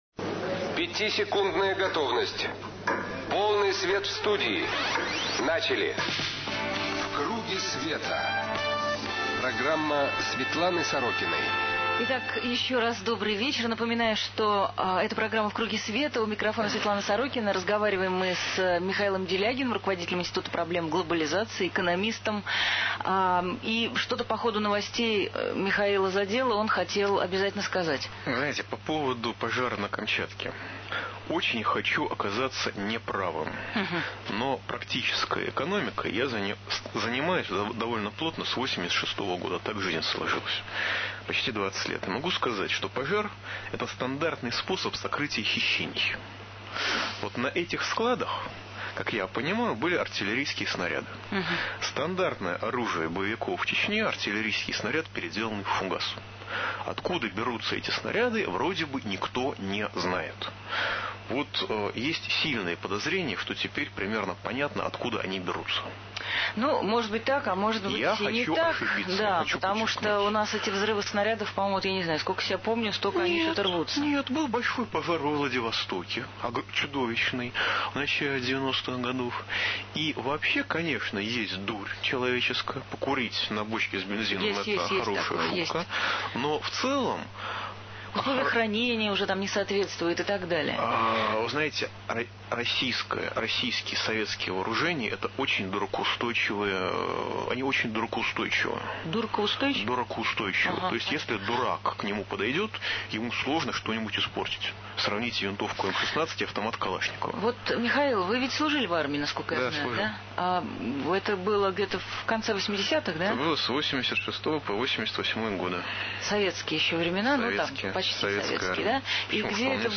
В круге света. Светлана Сорокина на радио "Эхо Москвы"